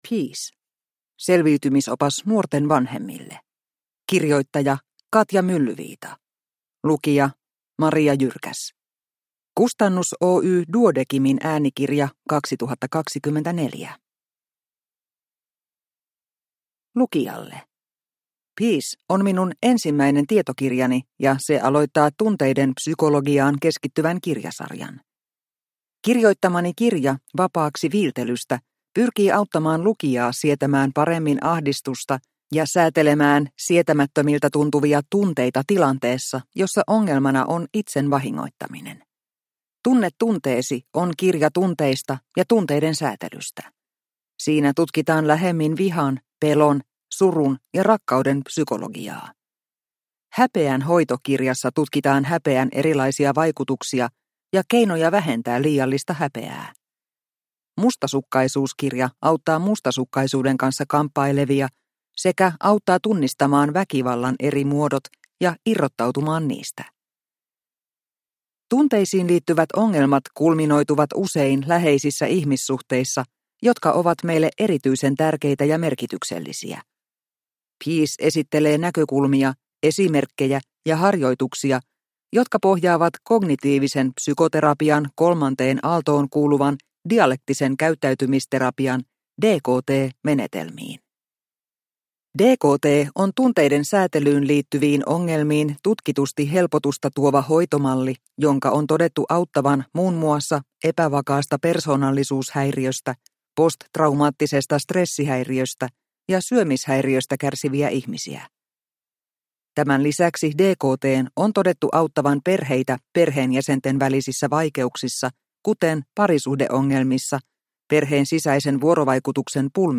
Peace! – Ljudbok – Laddas ner